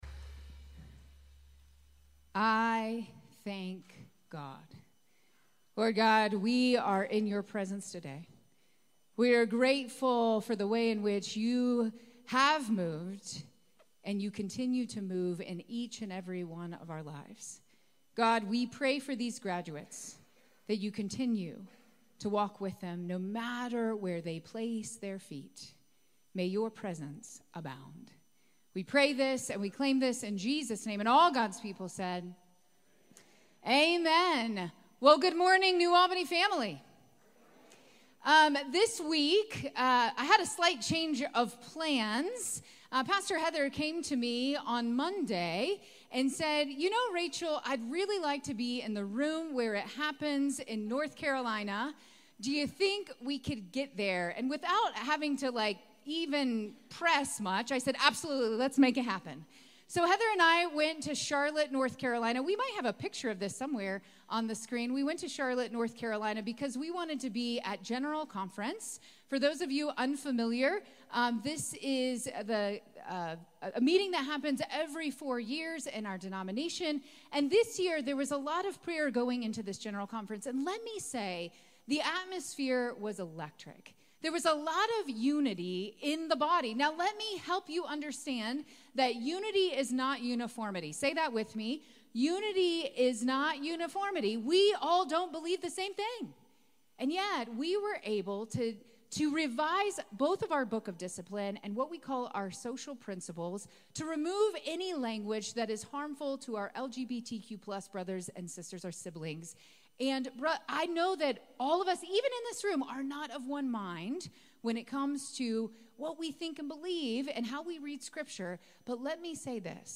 May 5, 2024 Sermon